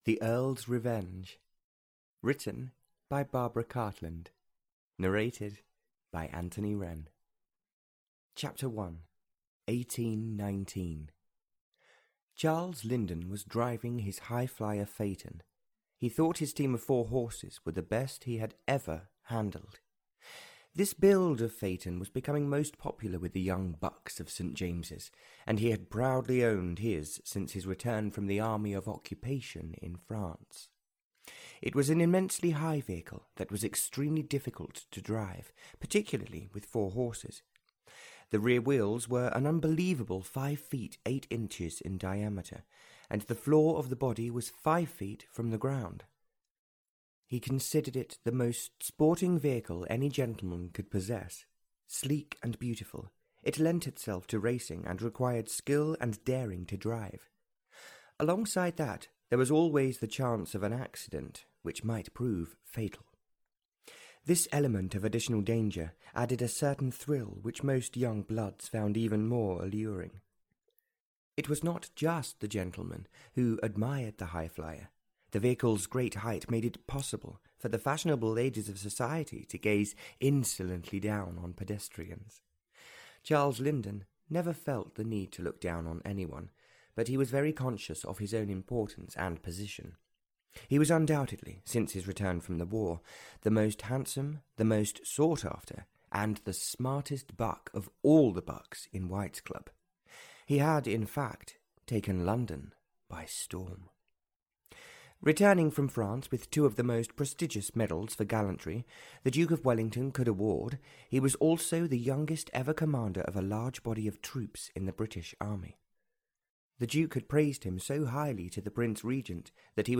Audio knihaThe Earl's Revenge (Barbara Cartland's Pink Collection 53) (EN)
Ukázka z knihy